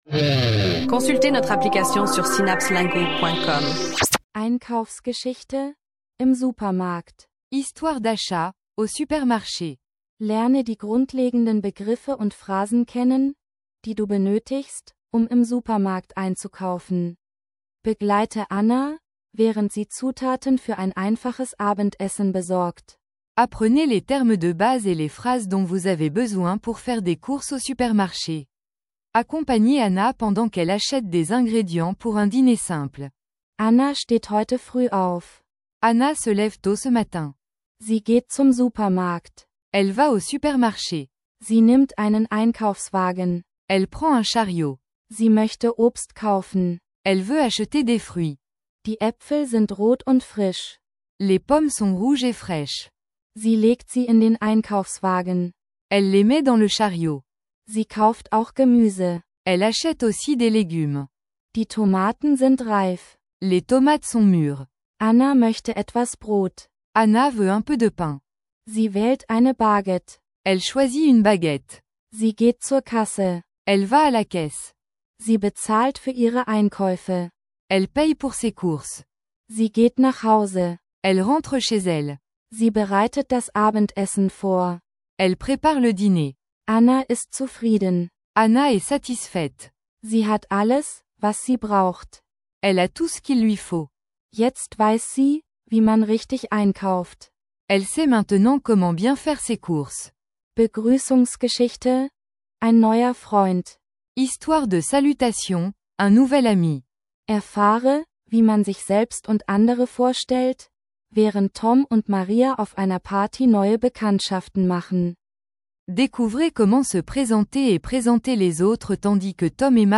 Plongez dans un monde passionnant d’apprentissage des langues avec des textes d’apprentissage divertissants et des chansons entraînantes dans différentes combinaisons de langues.
Écoutez, apprenez et profitez de mélodies accrocheuses qui vous aideront à maîtriser la langue de manière ludique.